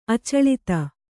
♪ acaḷita